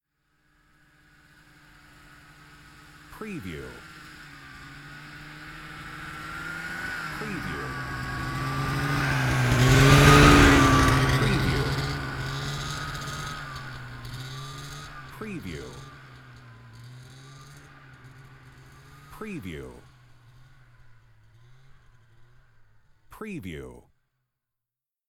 Snowmobile: pass by slow sound effect .wav #2
Description: The sound of a snowmobile passing by (slow speed)
Properties: 48.000 kHz 24-bit Stereo
Keywords: snowmobile, snow mobile, skidoo, ski-doo, ski doo, winter, passby, pass by
snowmobile-pass-by-slow-preview-02.mp3